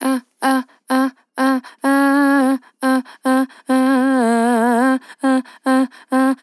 AH AH AH AH Sample
Categories: Vocals Tags: AH, dry, english, female, fill, sample, Tension